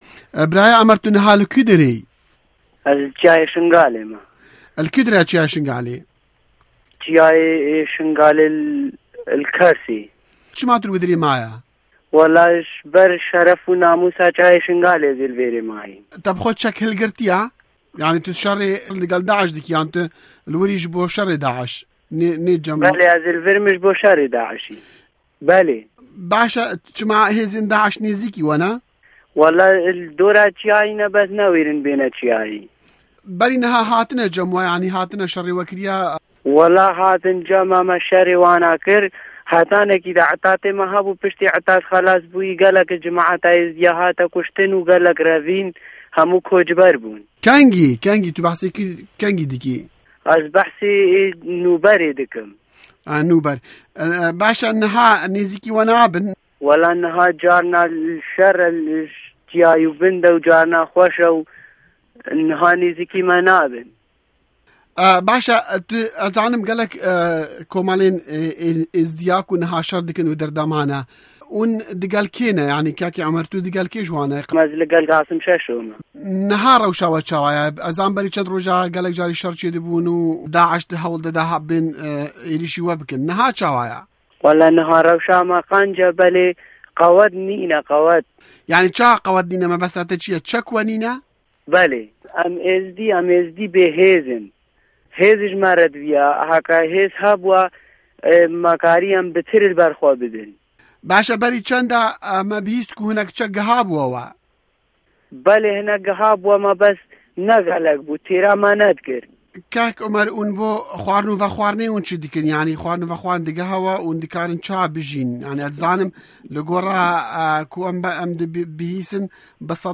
2 Hevpeyvînên Taybet li ser Rewşa Şengalê